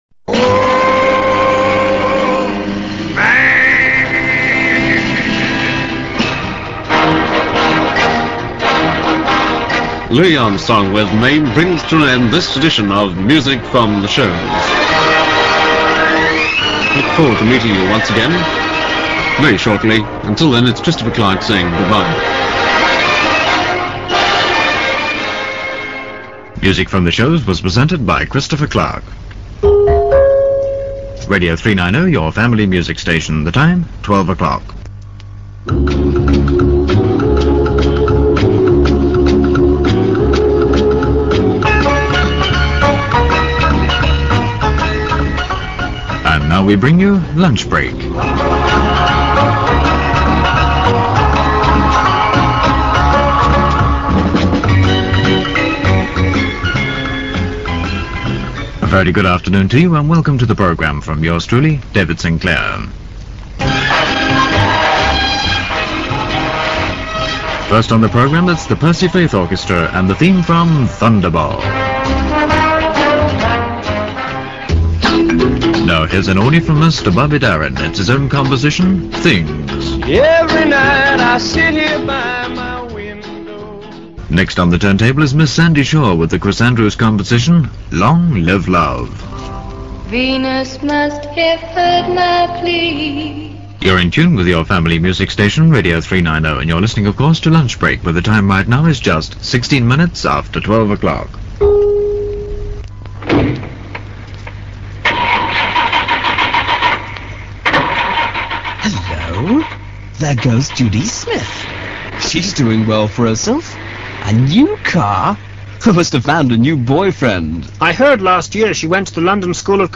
Strange to hear such a sexist commercial on ‘Eve, the woman's magazine of the air’!